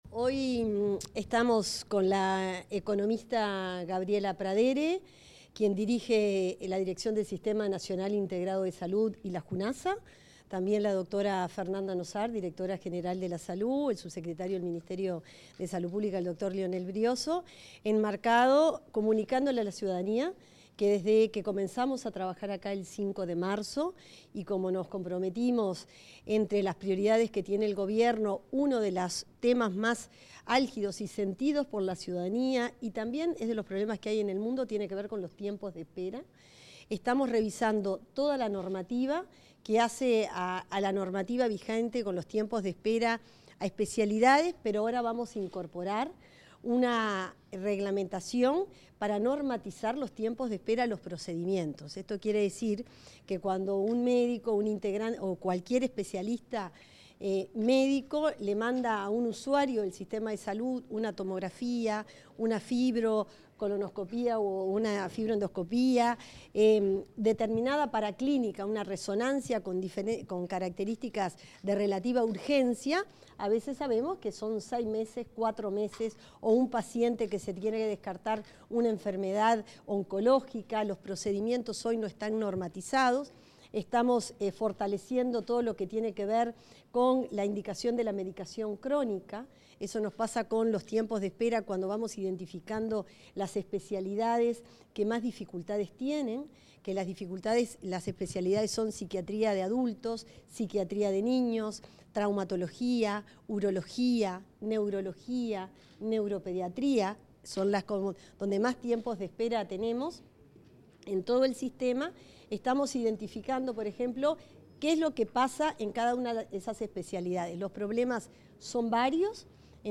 Declaraciones de autoridades del Ministerio de Salud Pública 03/07/2025 Compartir Facebook X Copiar enlace WhatsApp LinkedIn La ministra de Salud Pública, Cristina Lustemberg; la directora del Sistema Nacional Integrado de Salud, Gabriela Pradere, y la directora general de Salud, Fernanda Nozar, efectuaron declaraciones a la prensa sobre una estrategia integral, en la que están trabajando, para reducir los tiempos de espera en la atención.